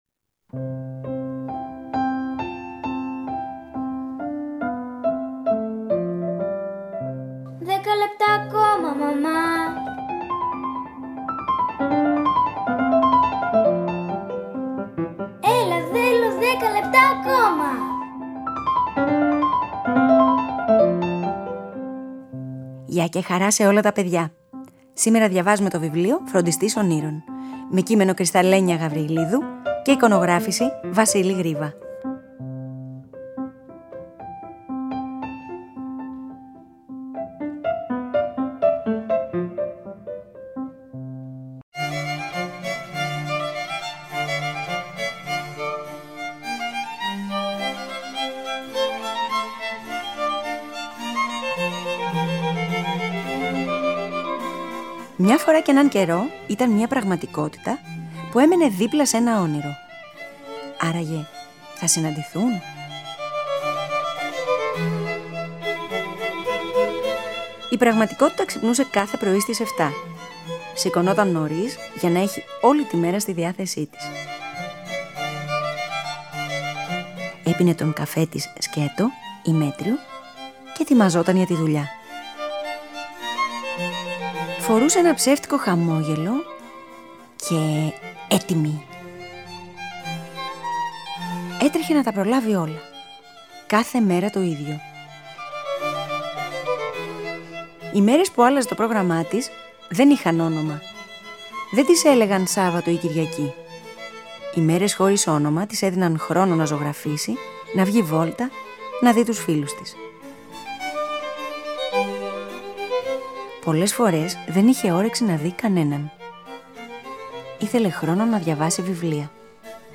Άραγε συναντήθηκαν; Ένα βιβλίο για την σημασία της ισορροπίας ανάμεσα στα όνειρα και την πραγματικότητα. Αφήγηση-Μουσικές επιλογές